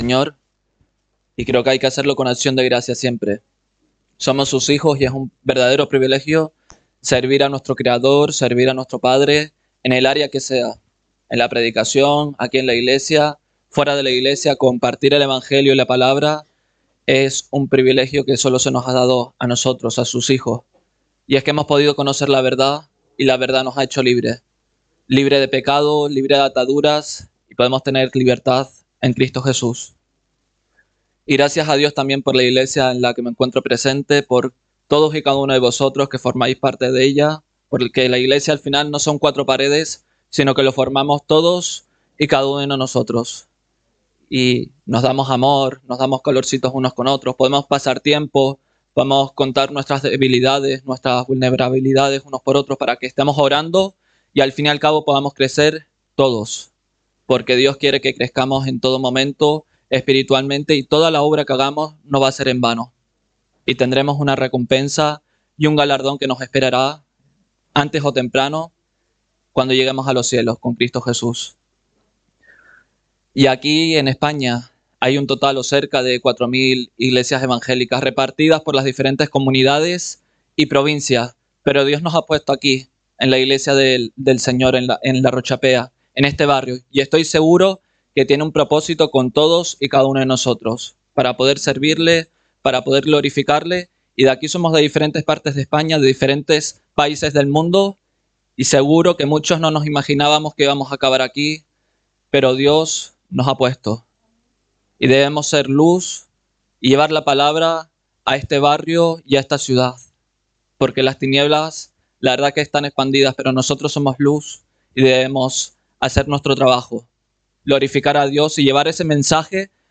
Predicación